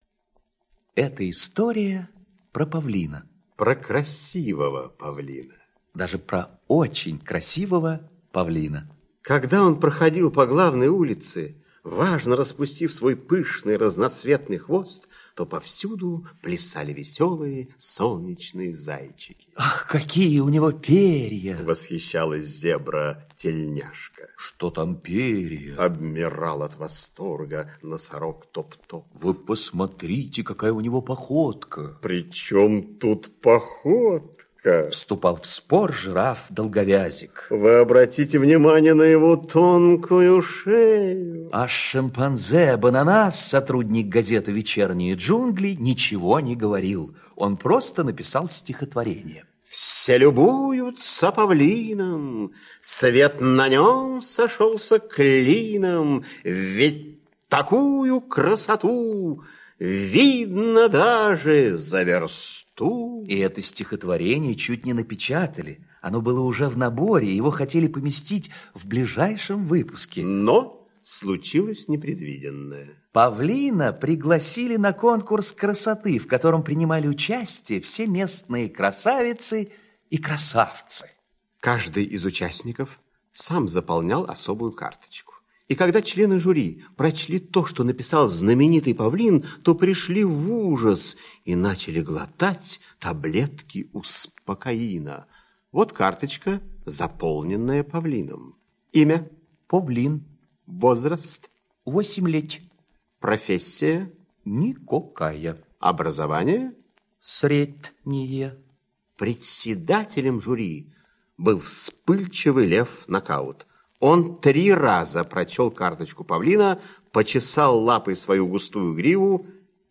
Слушайте Конкурс красоты - аудиосказка Пляцковского М.С. Сказка про конкурс красоты среди животных и красивого, но очень безграмотного Павлина.